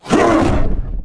attack2.wav